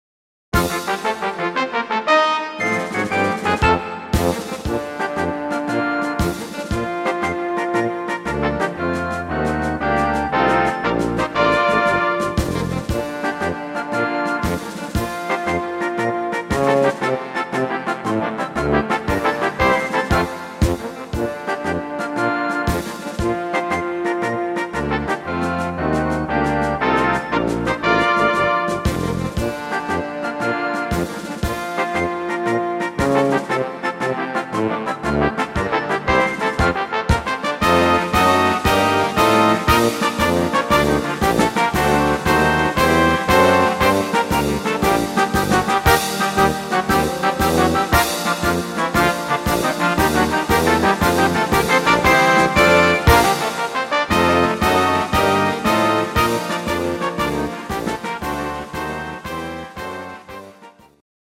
instr. Blasmusik